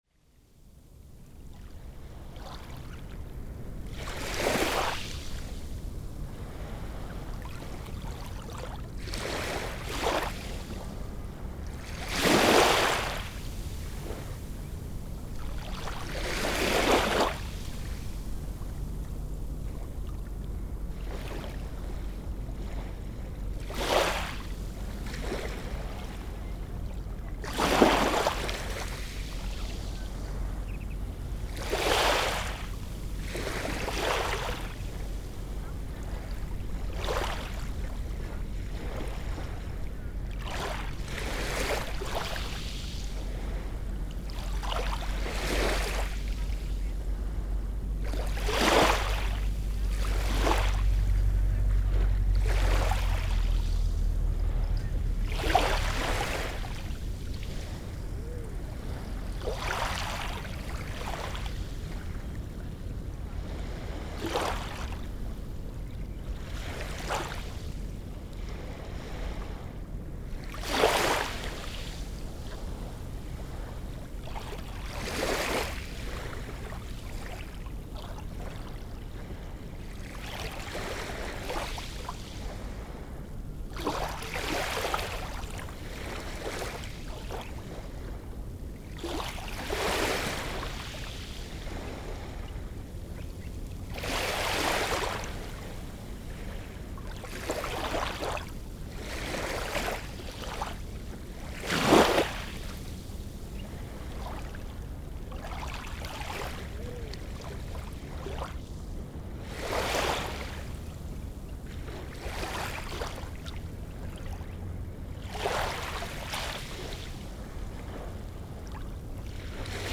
Posted in Mannlíf, Náttúra, tagged Birds, Fuglar, Grófin, ORTF, Seashore, Sennheiser MKH8040, Skeljanes, Sound Devices 788, Traffic noise, Waves on 16.5.2013| 1 Comment »
At 13th of May I cycled to the shore, west side of Reykjavik to make some microphones setup test in quiet environment.
This coastline is mostly unspoiled from humans works so there is miscellaneous birdlife.
As usual where nature are close to humans automotive world there is a deep rumbling noise, a terrifying noise from burning fossil fuel.
En í bakgrunni heyrast drunur frá vítisvélum borgarbúa sem fyrr en síðar munu breyta þessum ljúfu vorhljóðum í fjörunni.
Mics: Sennheiser MKH8040 (ORTF setup)
t157_noise-from-mordor.mp3